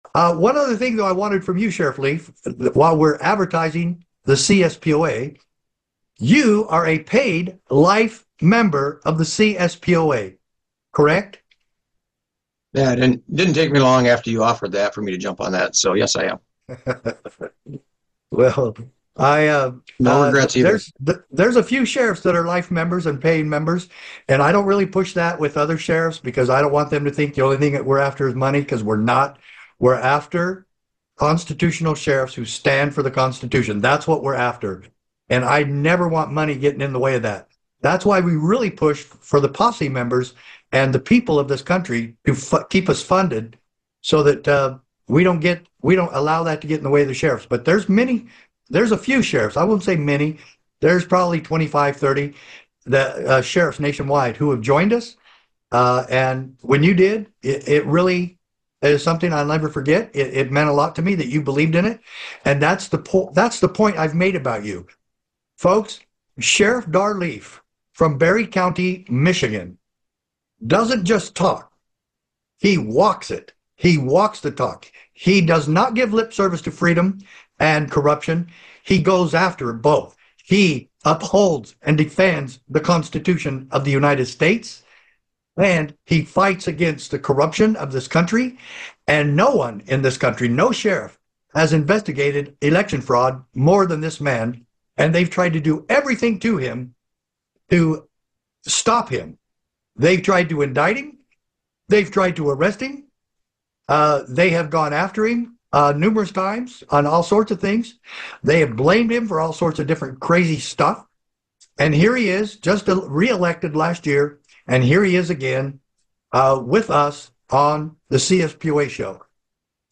➡ The CSPOA talks with Sheriff Leaf, a dedicated member of the CSPOA, who is praised for his commitment to upholding the Constitution and fighting corruption.